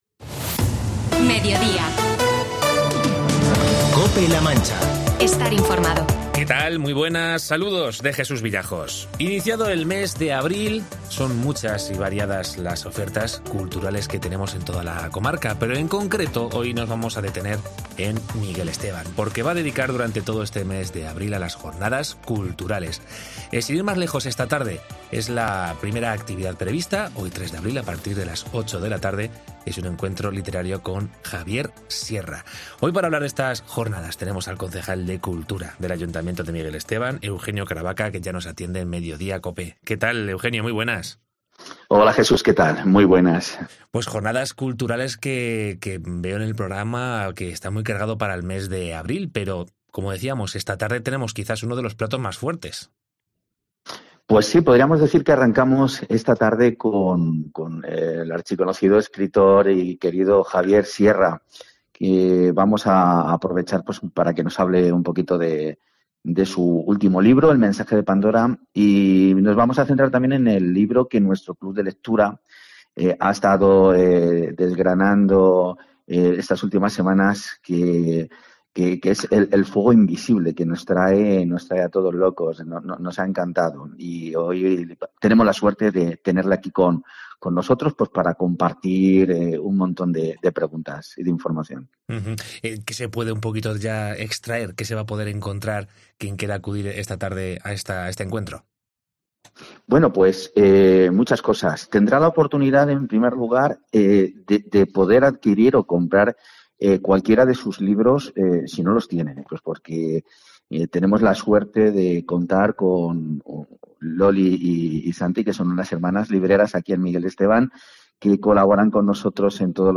Entrevista con Eugenio Caravaca, concejal de cultura del Ayuntamiento de Miguel Esteban
Hoy, hablamos con el concejal de cultura del ayuntamiento miguelete, Eugenio Caravaca.